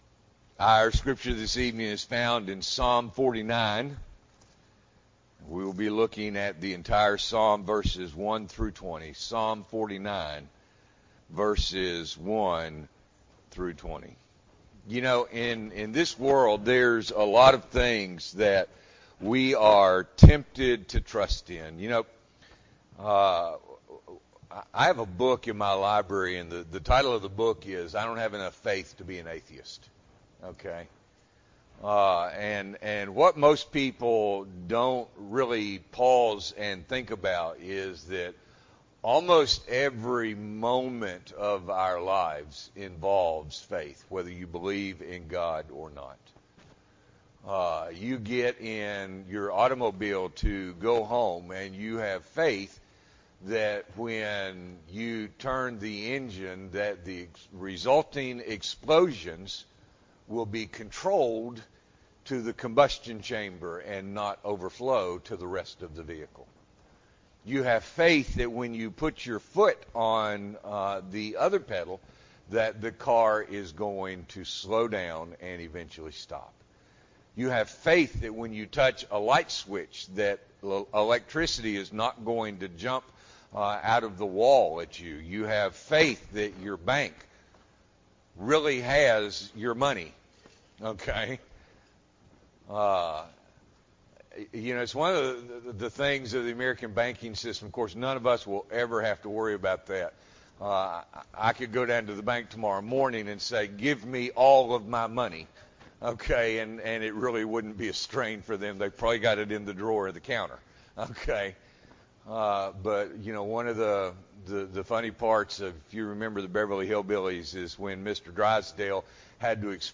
October 1, 2023 – Evening Worship